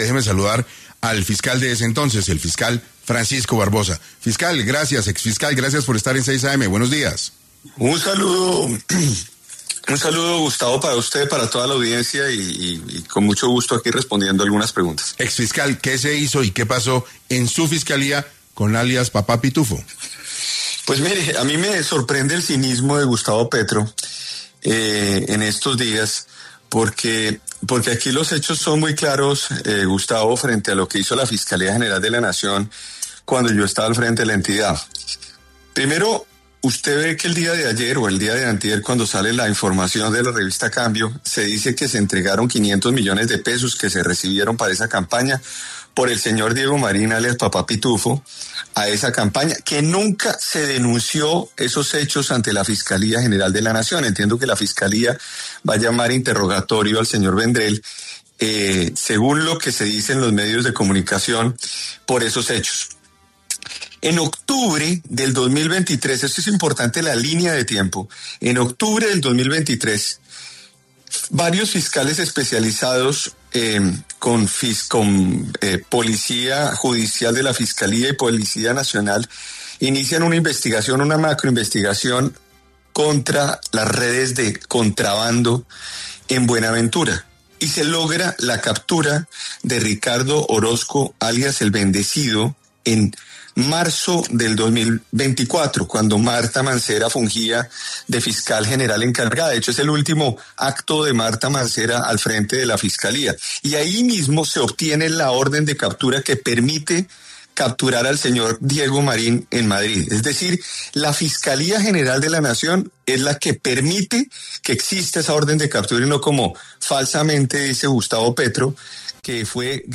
En ese orden de ideas, con la controversia creciendo y afectando la estabilidad del Ejecutivo, el exfiscal Francisco Barbosa, estuvo tras los micrófonos de 6AM para abordar este conflicto.